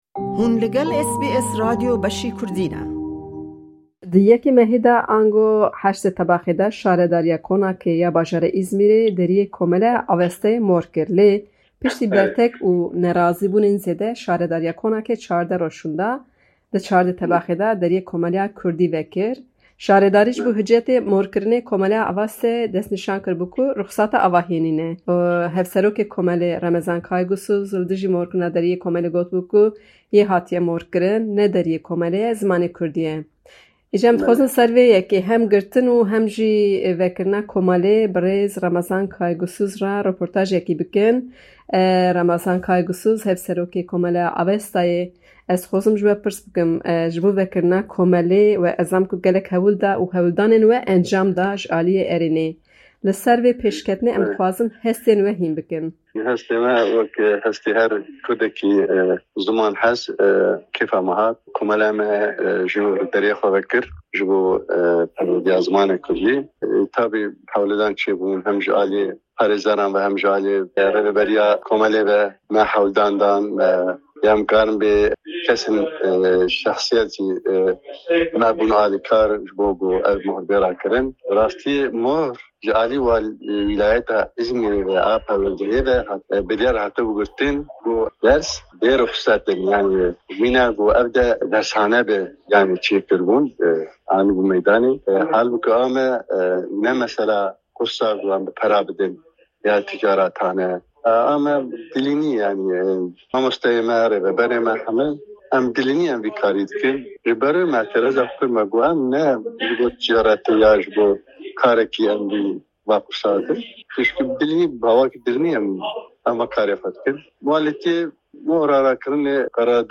hevpeyvînek